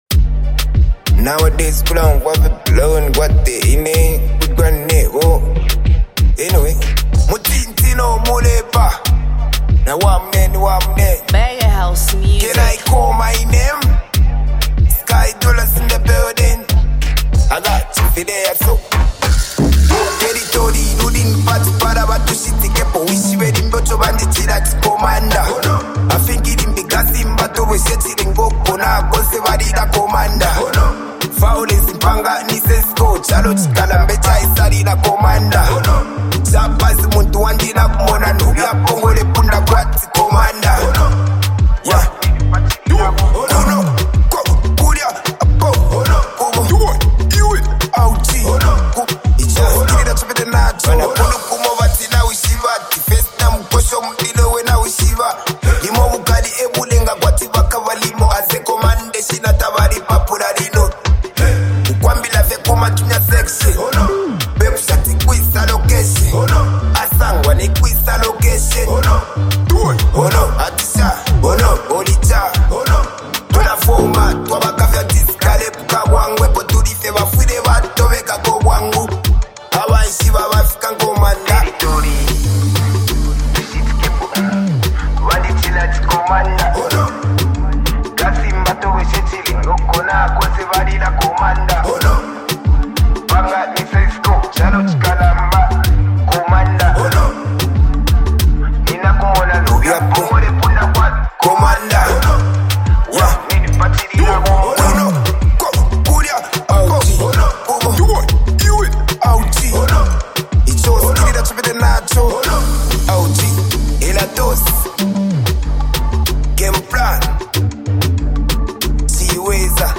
MusicZambian Music